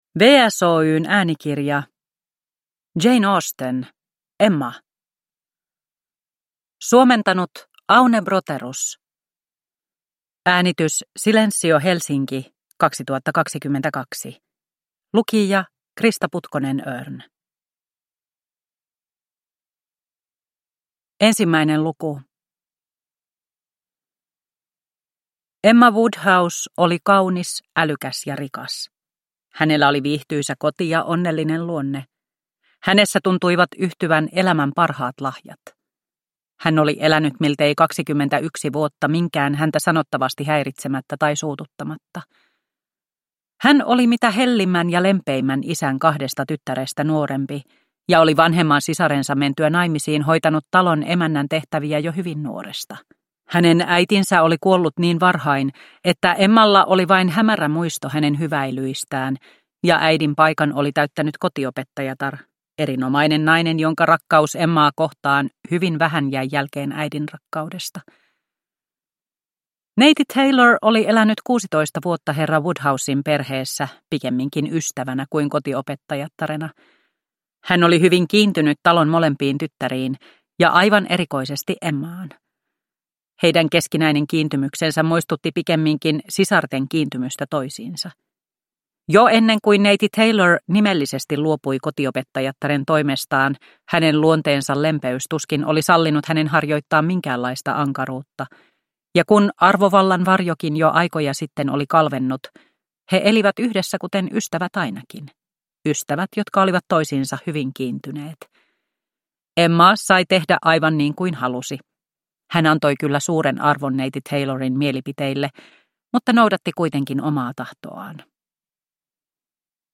Emma – Ljudbok – Laddas ner